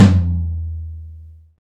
TOM XTOMLO0M.wav